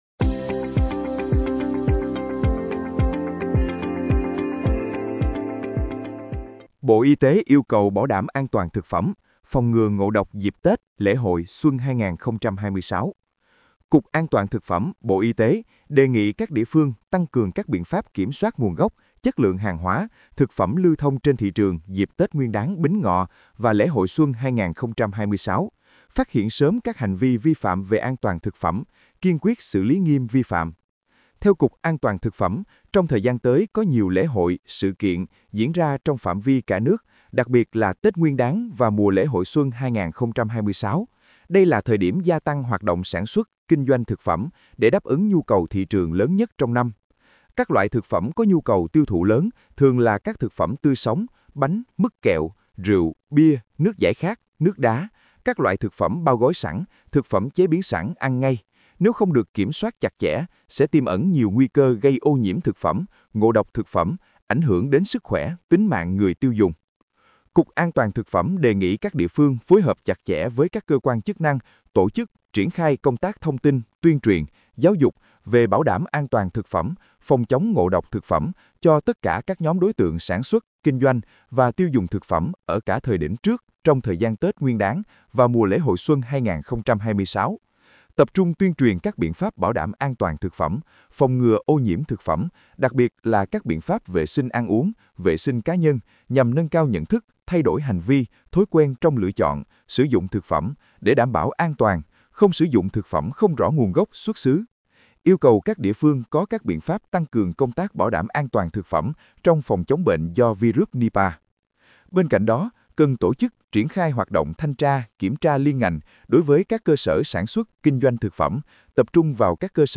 Bài phát thanh Bộ Y tế yêu cầu bảo đảm ATTP phòng ngừa ngộ độc dịp Tết Bính Ngọ và lễ hội Xuân 2026
Bài phát thanh Bộ Y tế yêu cầu bảo đảm ATTP.mp3